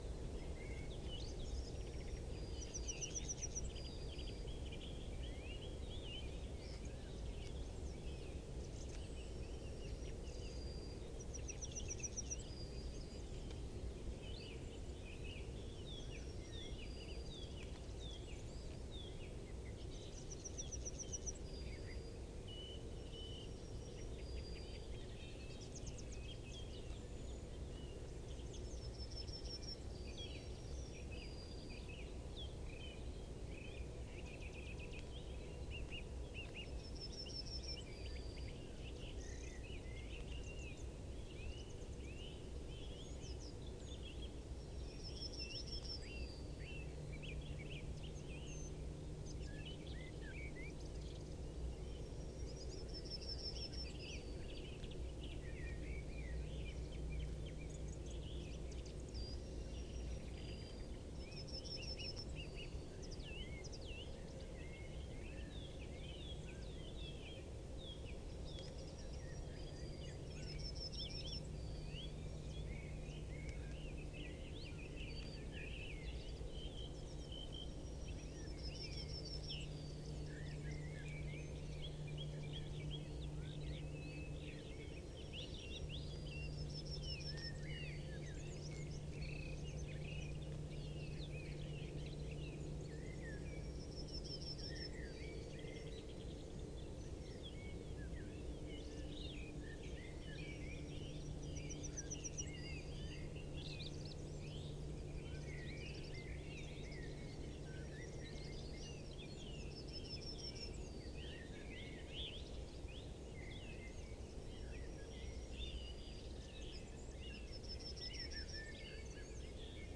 Fringilla coelebs
Turdus philomelos
Alauda arvensis
Sylvia communis
Emberiza citrinella